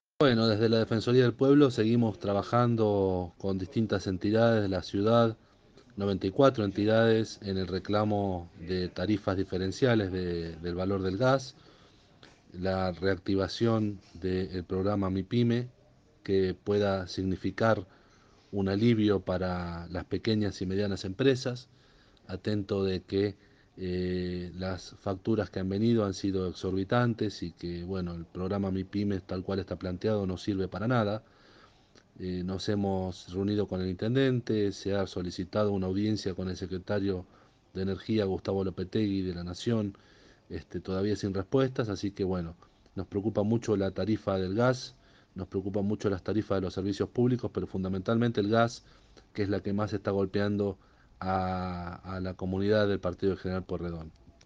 Así lo aseguró Fernando Rizzi, uno de los defensores del pueblo del Partido de General Pueyrredón.
Fernando Rizzi dijo a Bien Despiertos, programa emitido de 7 a 9, por Radio de la Azotea, que “desde la Defensoría trabajan con 94 entidades de la ciudad en el reclamo de tarifas diferenciales del valor del gas”.